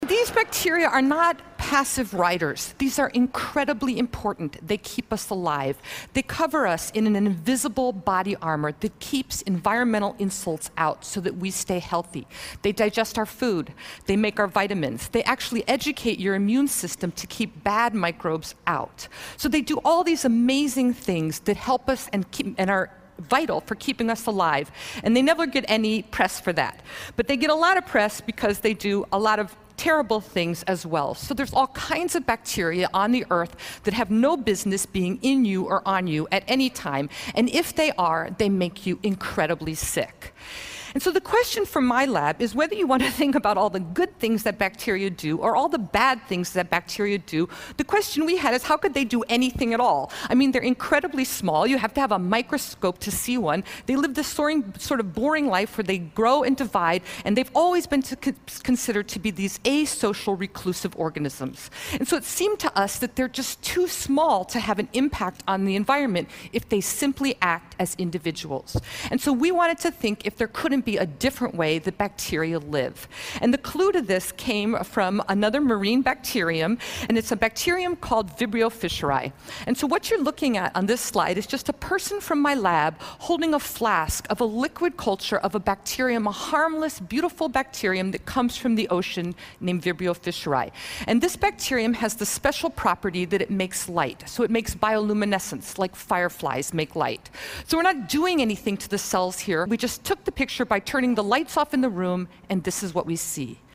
TED演讲：细菌是怎样交流的(2) 听力文件下载—在线英语听力室